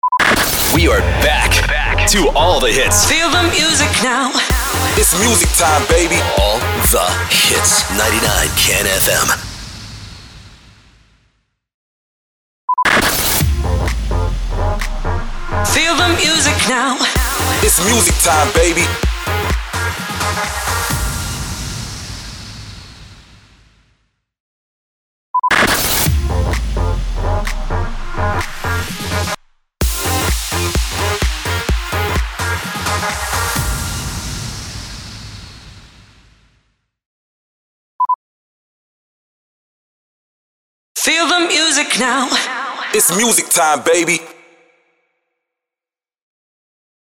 454 – SWEEPER – MUSIC REJOIN
454-SWEEPER-MUSIC-REJOIN.mp3